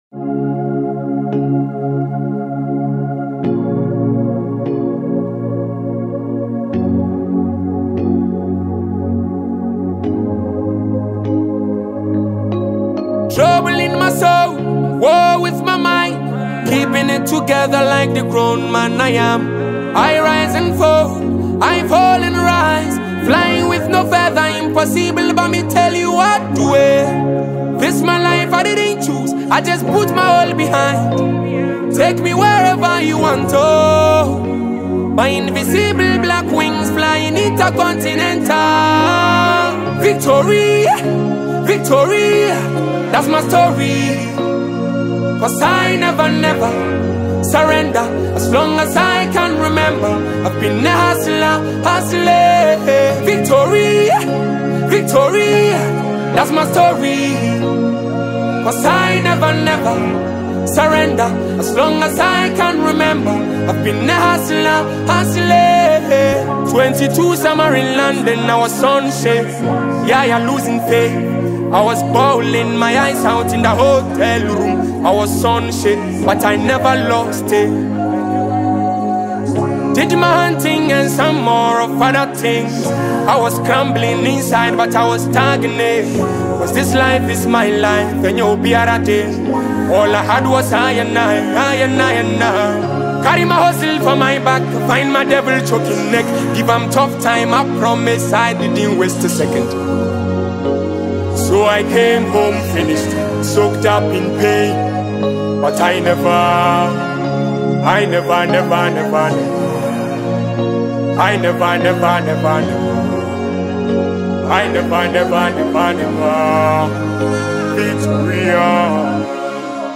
hiphop tune